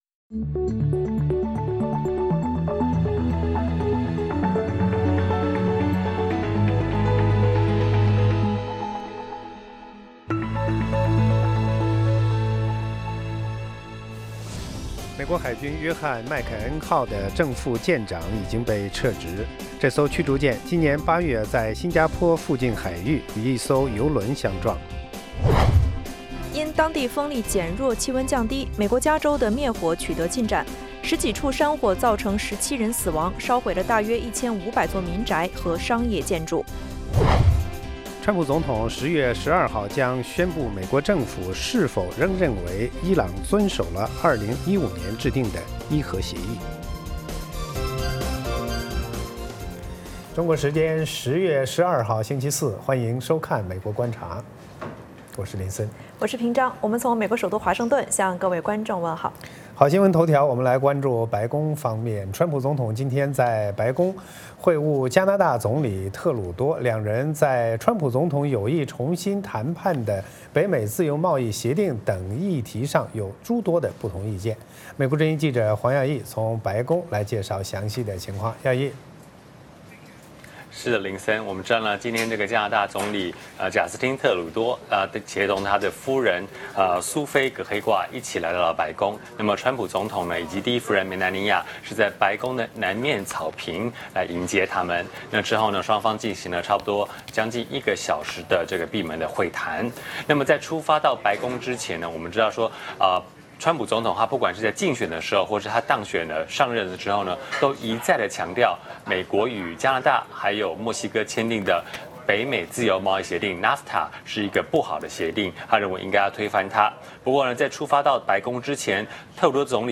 “VOA卫视 美国观察”掌握美国最重要的消息，深入解读美国选举，政治，经济，外交，人文，美中关系等全方位话题。节目邀请重量级嘉宾参与讨论。